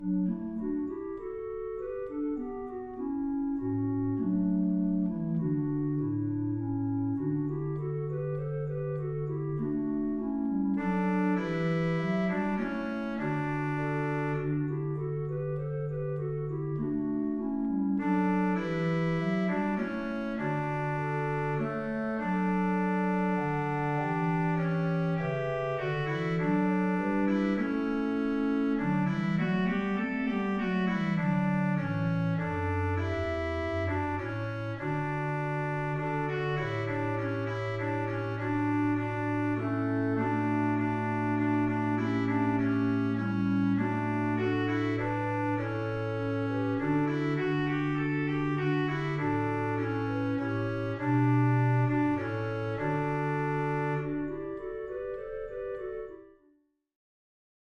Voicing: SA Men, upper/lower, accompanied or unaccompanied
Alto